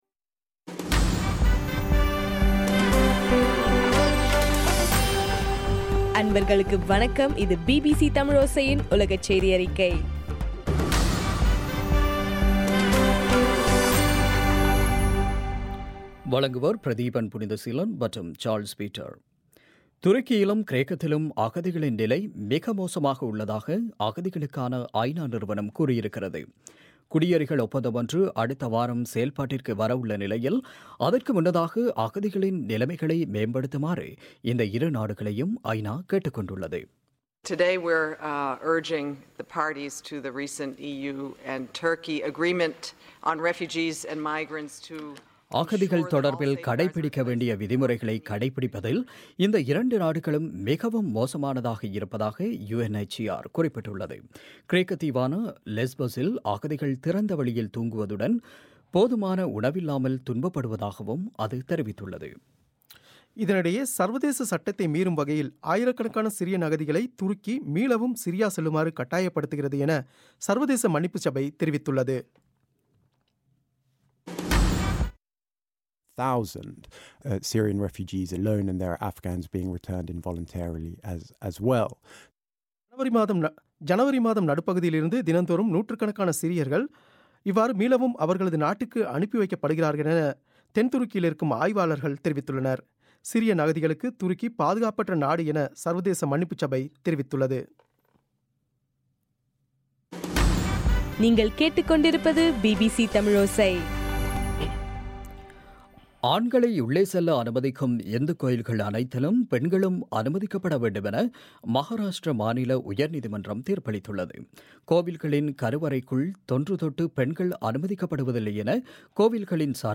ஏப்ரல் 1 பிபிசியின் உலகச் செய்திகள்